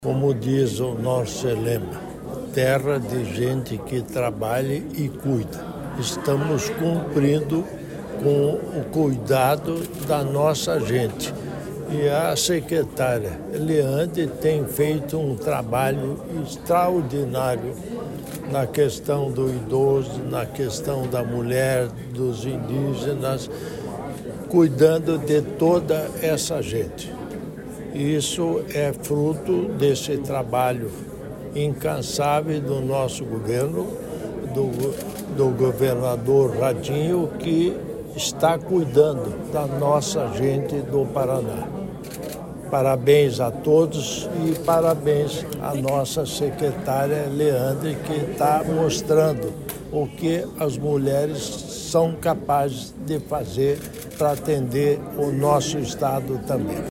Sonora do governador em exercício Darci Piana sobre a construção de complexos do idoso em quatro municípios